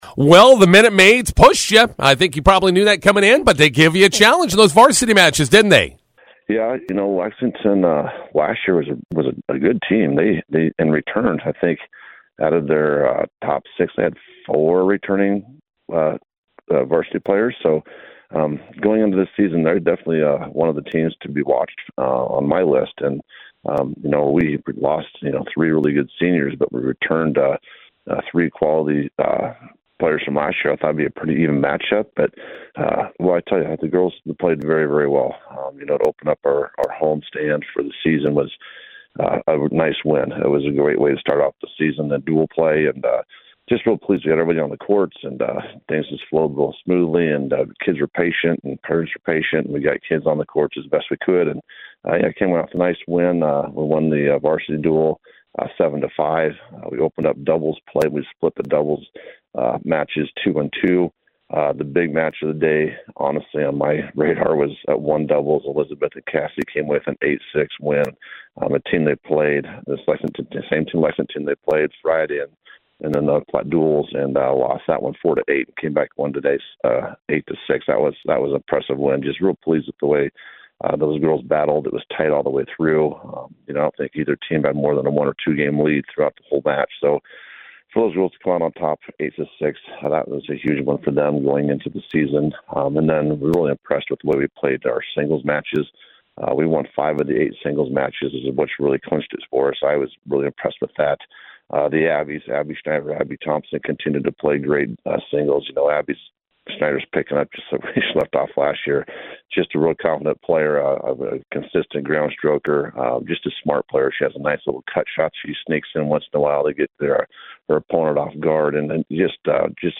INTERVIEW: Bison girls tennis win their opening dual vs. Lexington